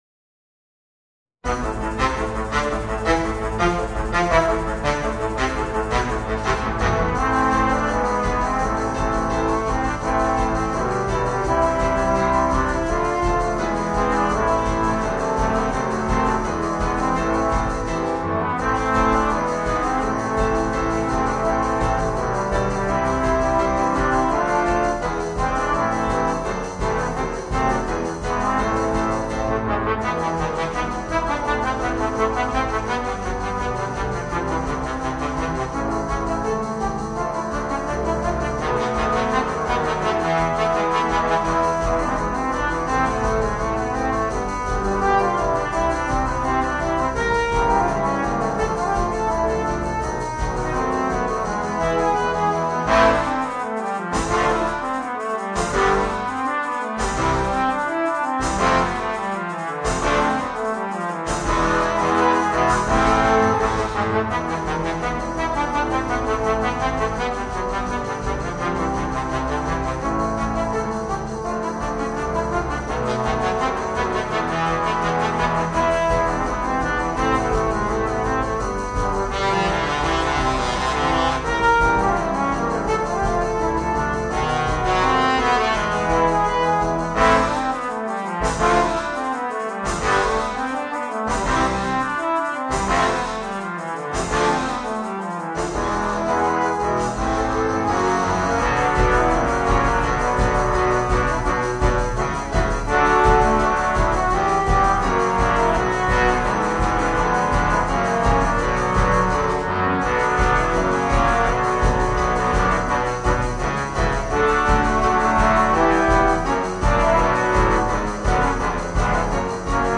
Voicing: 5 Trombones